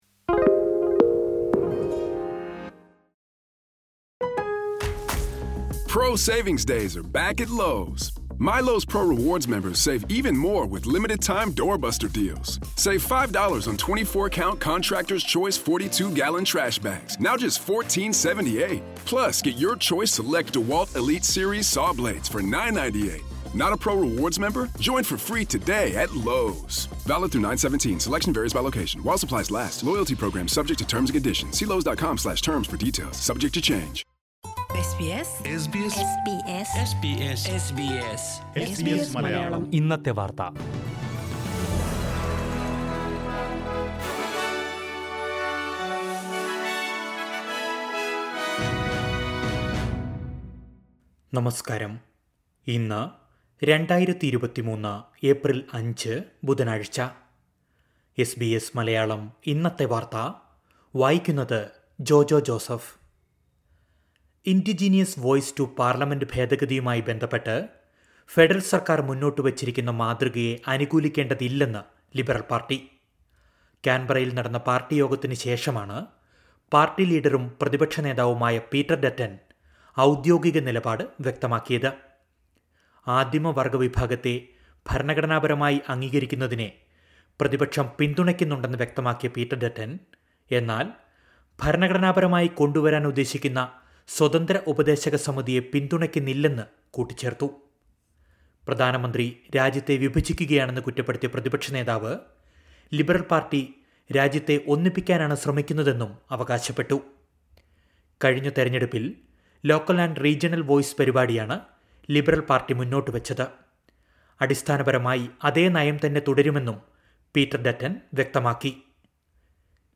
2023 ഏപ്രിൽ അഞ്ചിലെ ഓസ്ട്രേലിയയിലെ ഏറ്റവും പ്രധാന വാർത്തകൾ കേൾക്കാം...